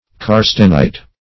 Karstenite \Kar"sten*ite\, n. Same as Anhydrite .